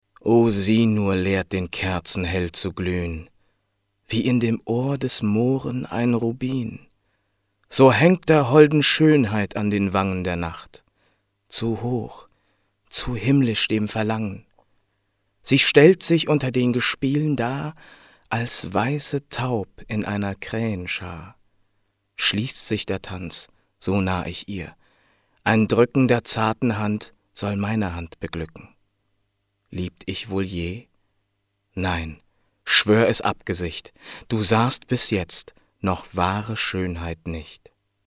Theaterschauspieler und Sänger
Sprechprobe: Sonstiges (Muttersprache):